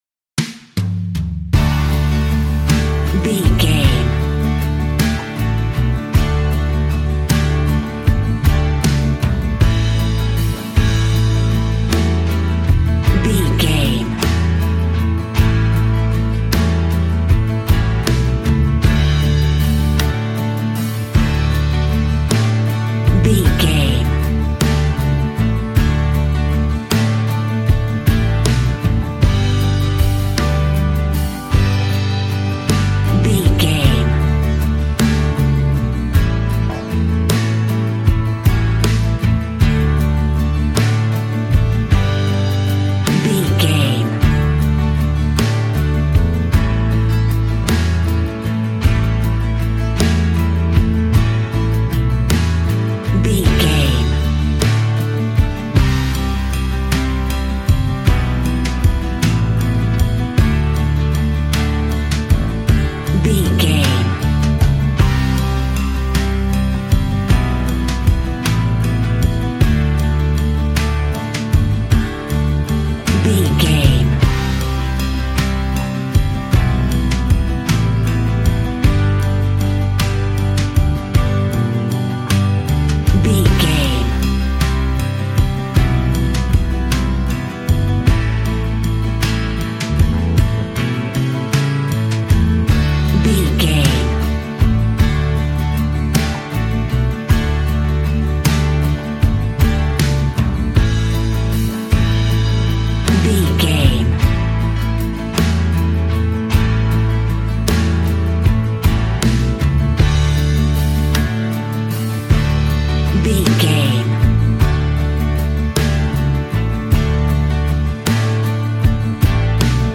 Ionian/Major
D
cheerful/happy
double bass
drums
piano